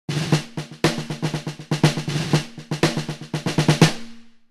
Picture and sound sample of a 1960s Kent blue sparkle snare drum
1960s Kent blue sparkle.
When I got this on ebay it still had the original throw-off and snare side head and the wires that came with it were in pretty rough shape.
kentbluesparkle.mp3